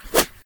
arrow-fly-by-1.ogg